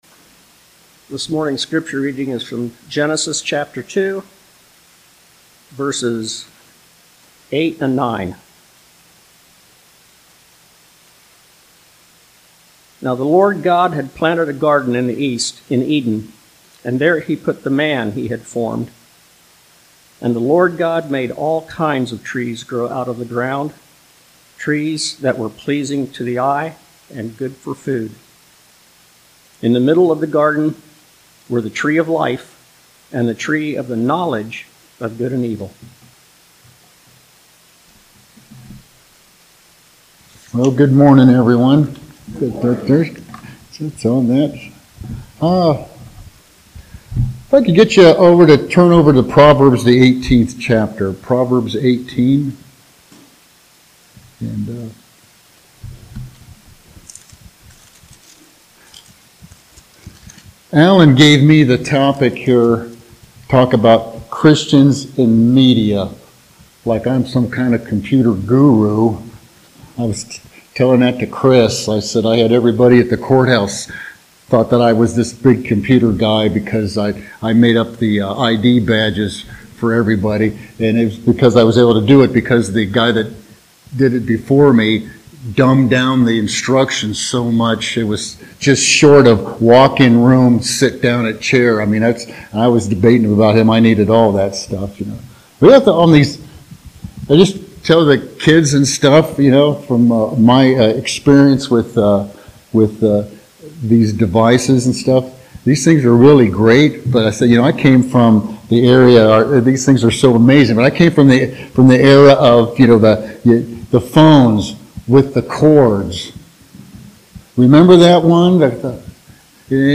Genesis 2:8-9 Service: Sunday Morning Topics